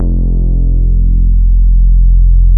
MODULAR F2P.wav